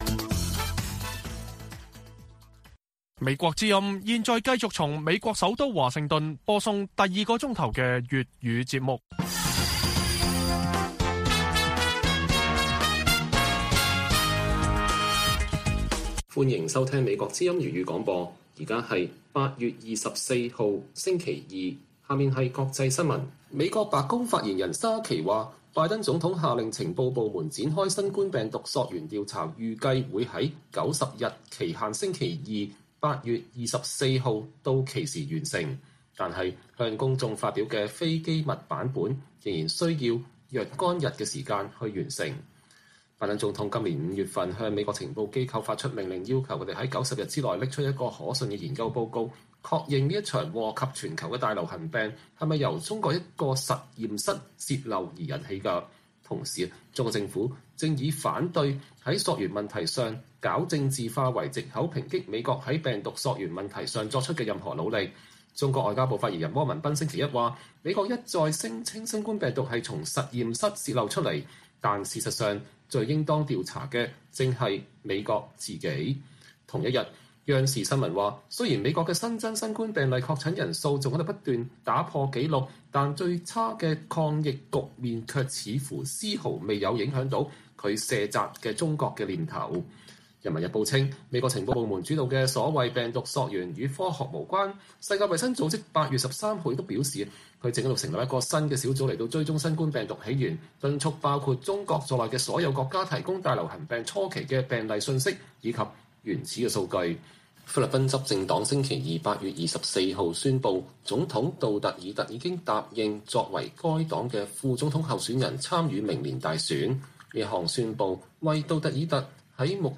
粵語新聞 晚上10-11點: 白宮稱90天新冠溯源調查將如期完成